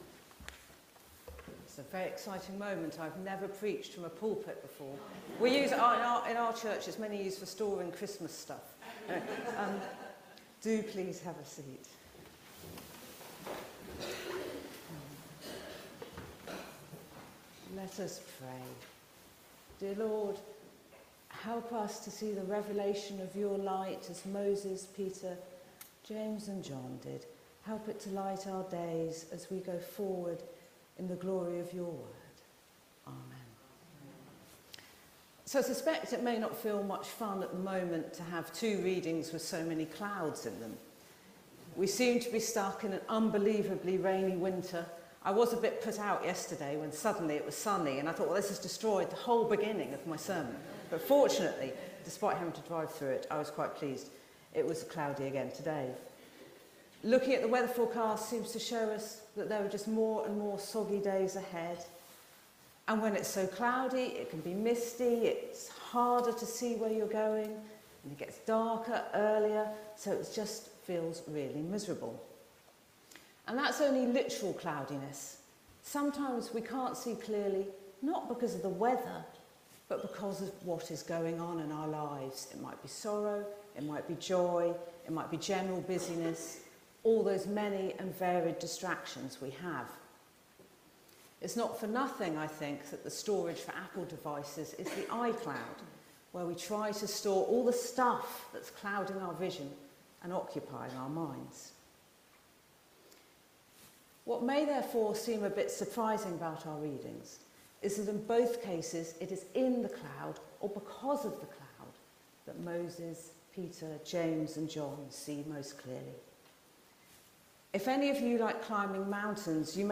HomeSermonsSee clearly through the clouds